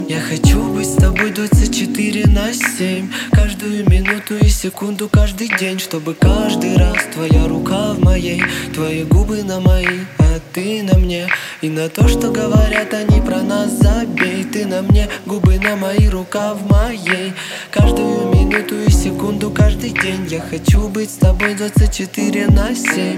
громкие
лирика
Хип-хоп
русский рэп